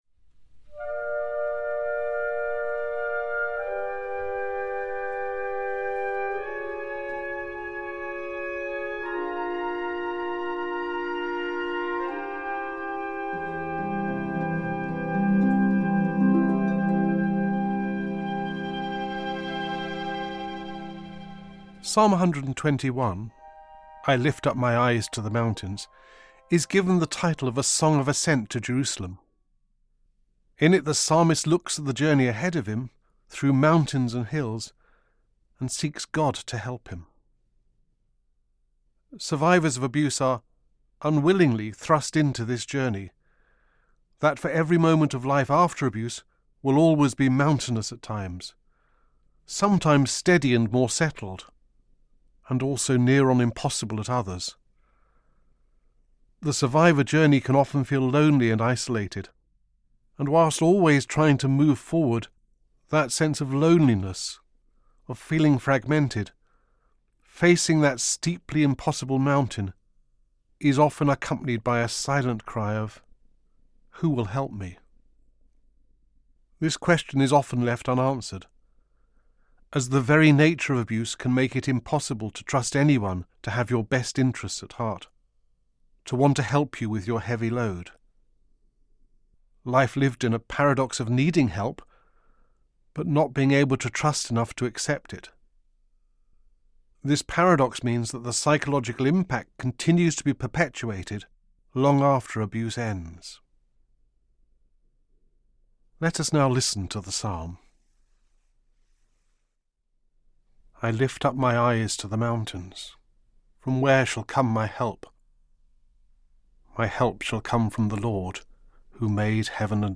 As an important part of our resources for the Day of Prayer for the Survivors of Abuse, we have produced an audio reflection on Psalm 121. We listen to an introduction before hearing a reading of the Psalm. Then a survivor offers her personal reflection on the psalm before we offer prayers for survivors of abuse, their families, friends and communities.